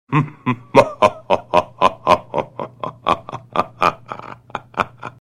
chuckle.wav